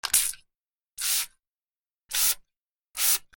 / M｜他分類 / L01 ｜小道具 / スプレー
スプレー 液体
『ブチュ』